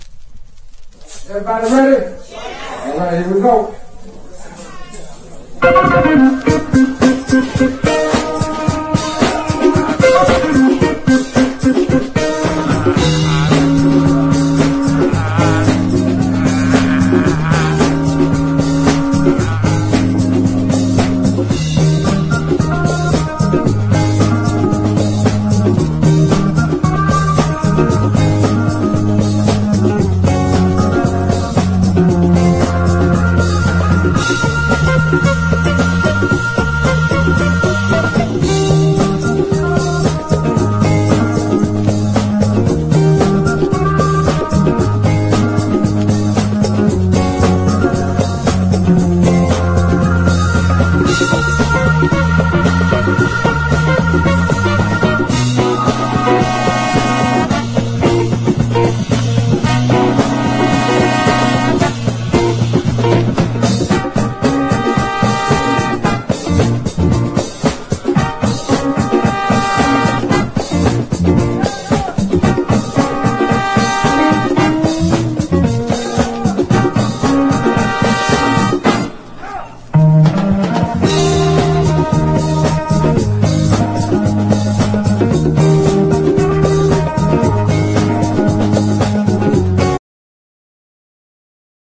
ソウル・ジャズ傑作！